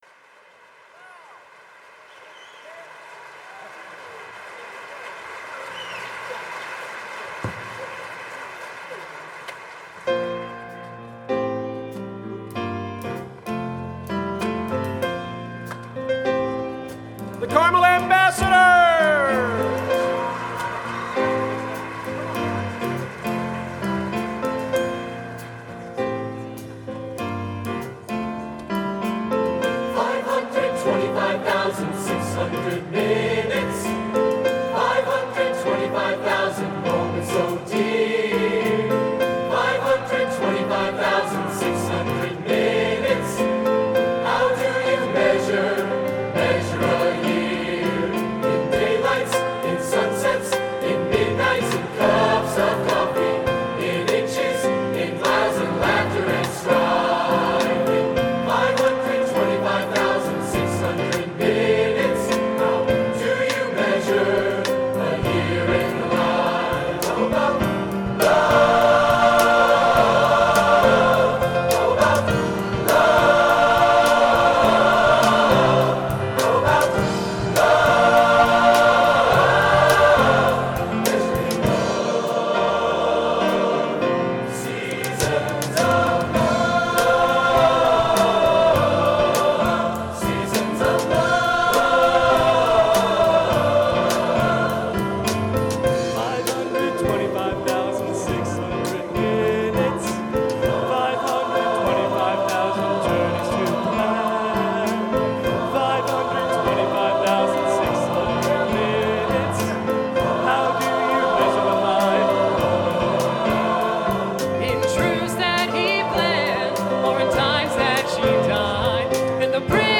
Location: Carmel High School, Carmel, Indiana
Genre: Broadway | Type:
Sung with the Carmel Choir.